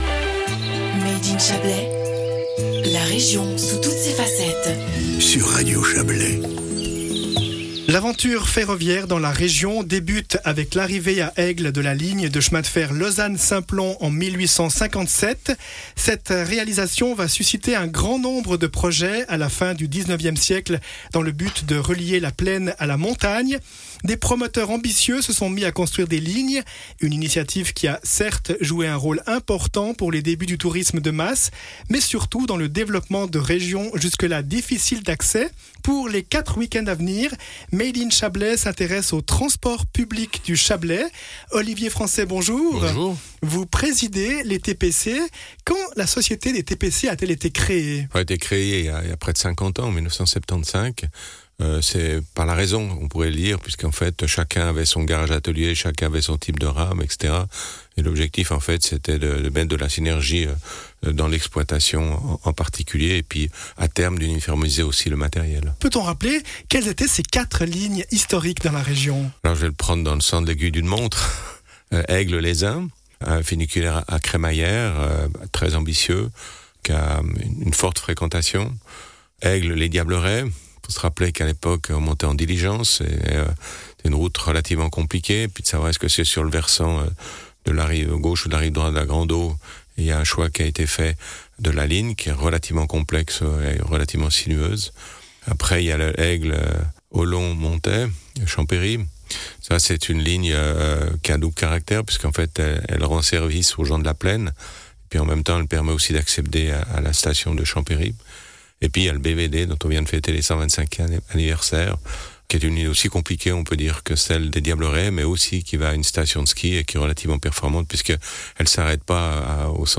Intervenant(e) : Olivier Français, président